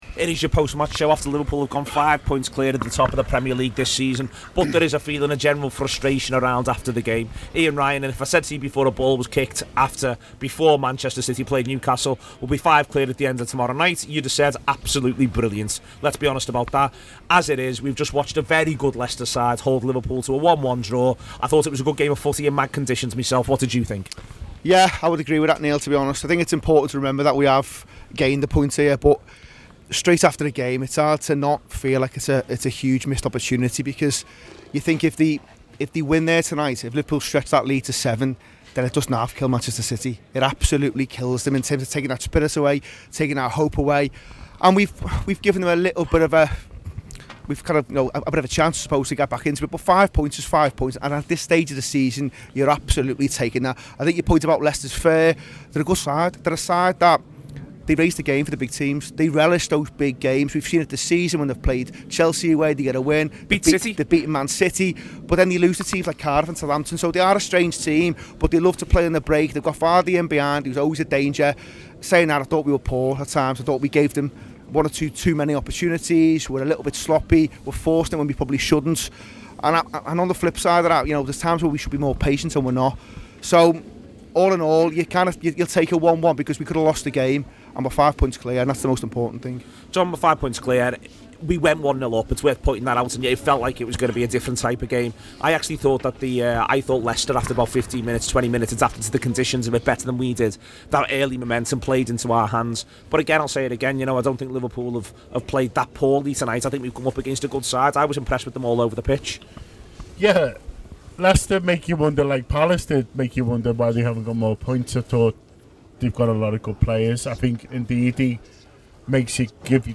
by | Jan 30, 2019 | app, Podcast, Post-Match Show, TAW Player | 1 comment